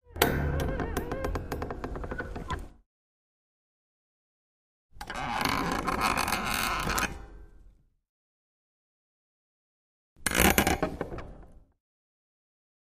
Springs, Bed, Heavy Creak x3